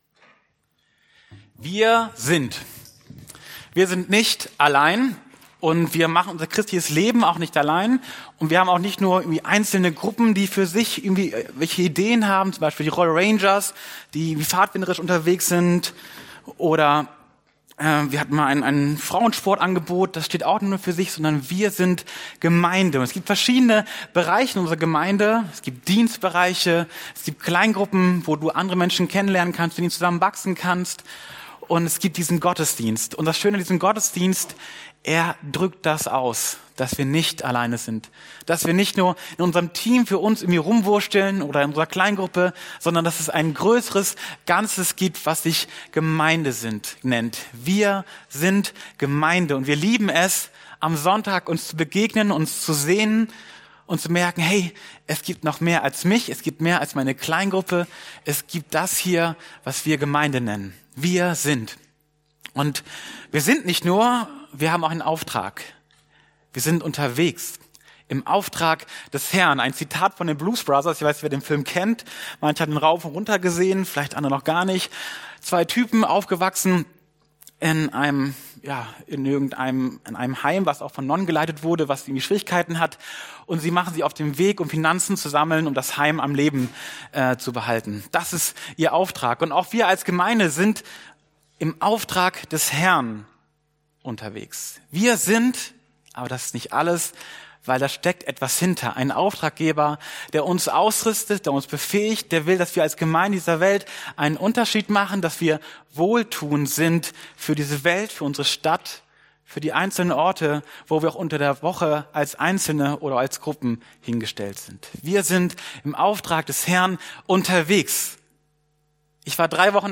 Das Video, das nach der Predigt abgespielt wurde, gibt es auf der Detailseite der Predigt zu sehen.